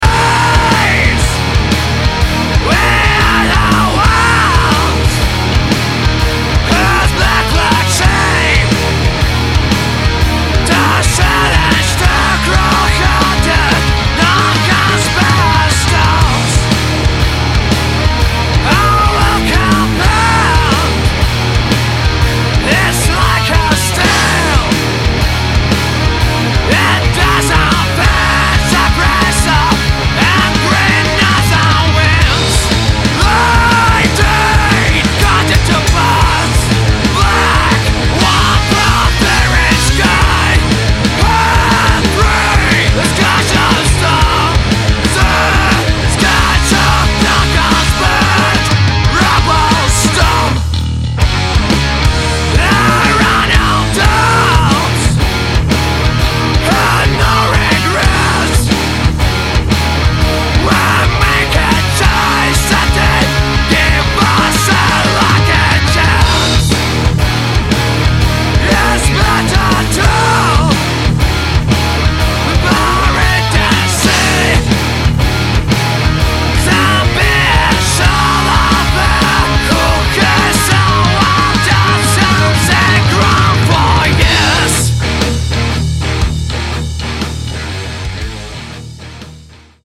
southern metal (��� �����)